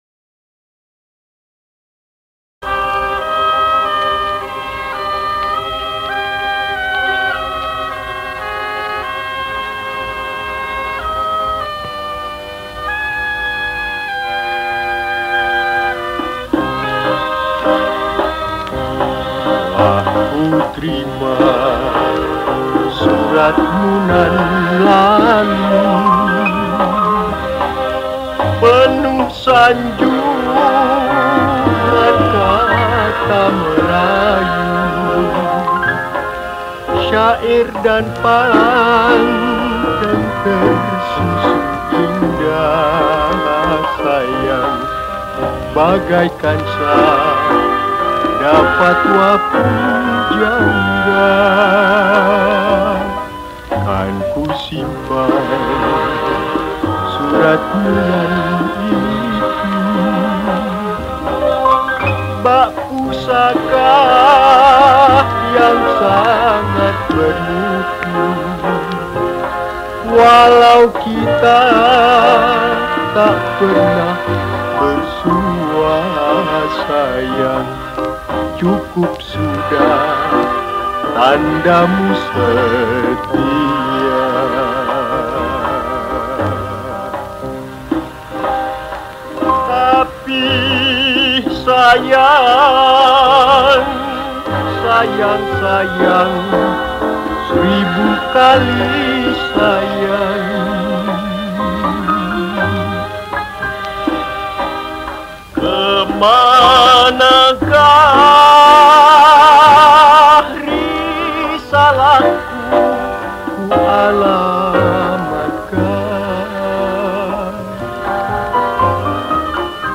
Indonesian Song
Skor Angklung